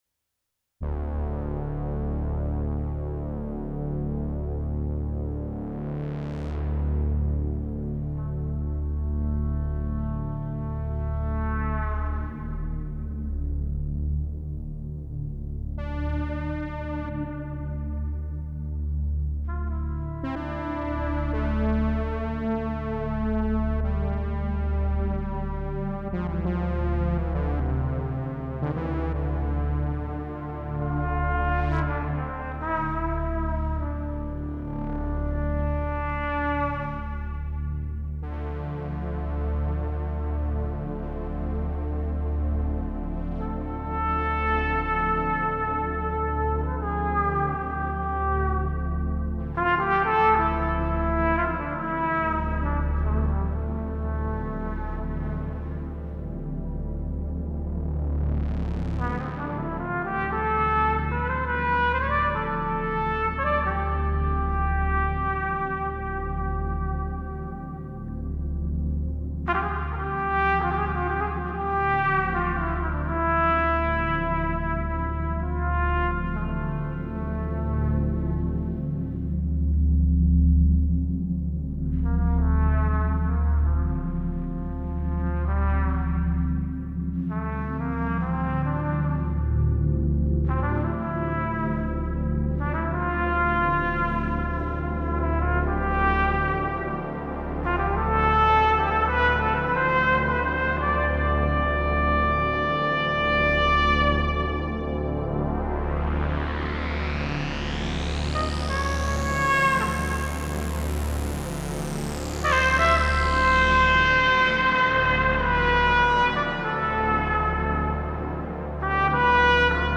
Sehr schnell, one-take, Trompete noch gar nicht warm gespielt.
Also die Trompete ist meine Trompete, nicht einer der sounds UB-Xas...